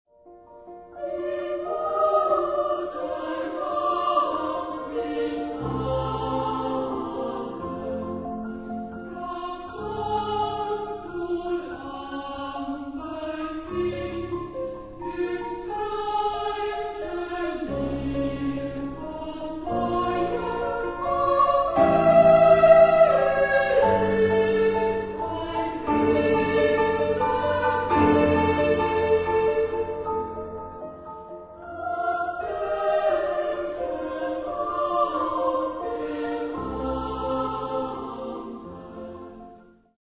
Genre-Style-Forme : Profane ; Chanson
Caractère de la pièce : Andante moderato
Type de choeur : SA  (2 voix égales de femmes )
Instrumentation : Piano à quatre mains  (1 partie(s) instrumentale(s))
Tonalité : si bémol majeur